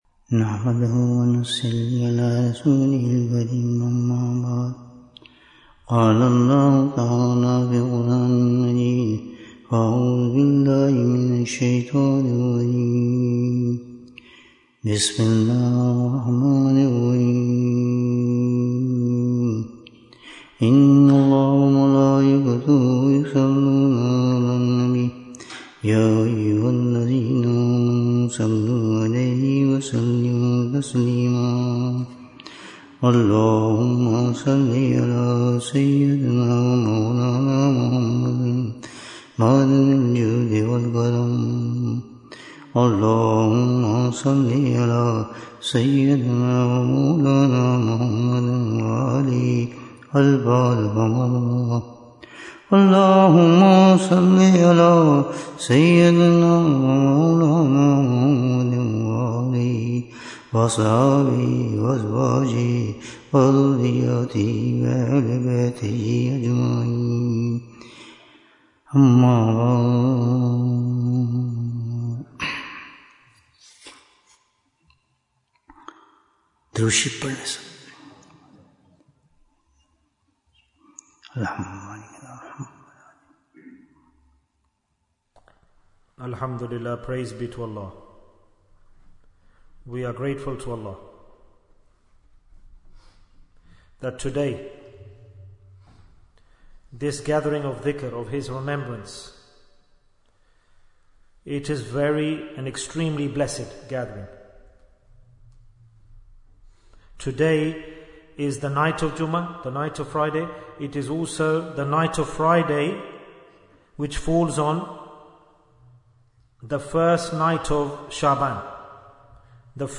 Bayan, 82 minutes30th January, 2025